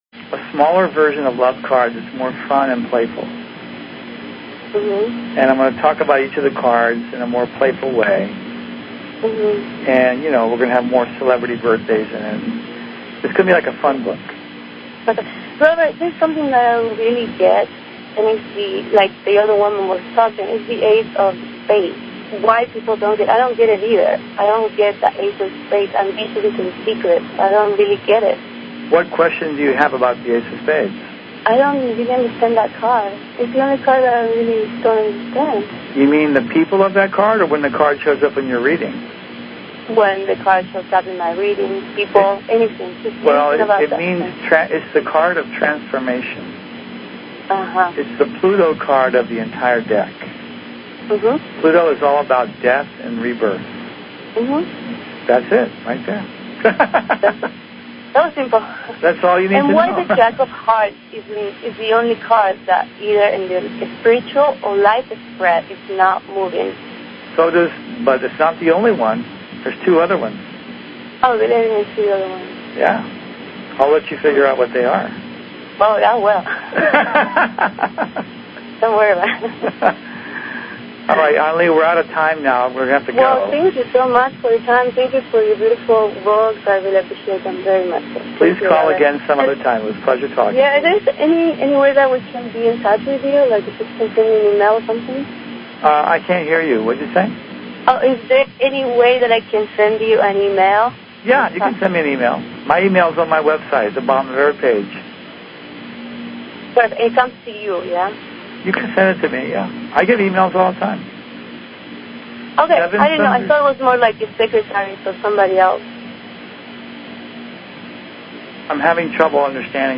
Talk Show